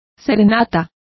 Complete with pronunciation of the translation of serenade.